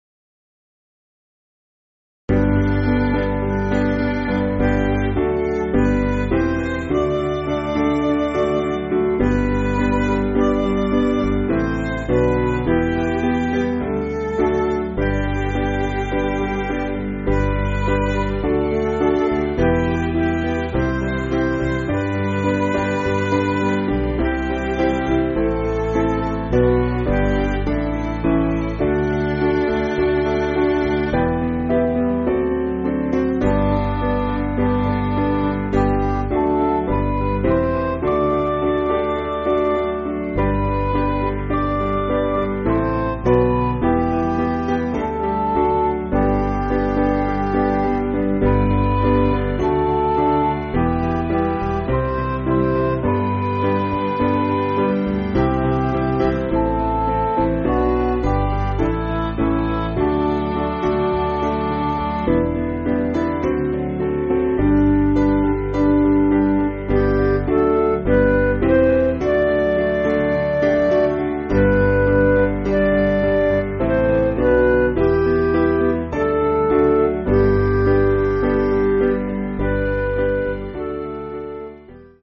Piano & Instrumental
(CM)   6/G
Midi